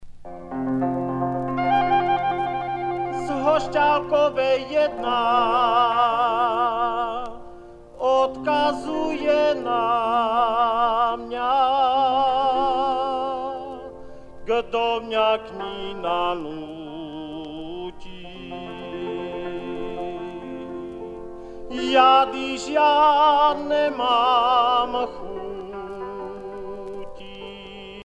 Folk singer from Moravian Wallachia
Pièce musicale éditée